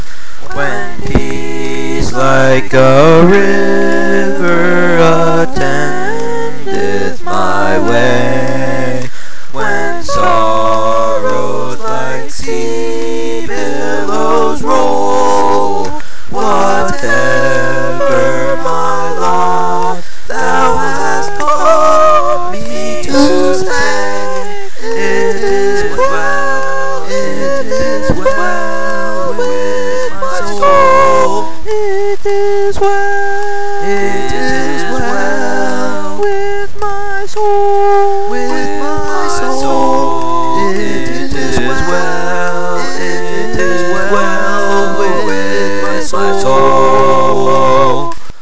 Wave files are sounds that you record with a microphone. The clicking noises in the background of some songs is my metronome.
(I sing each part) singing "It Is Well With My Soul" by Phillip Bliss and Horatio G. Spafford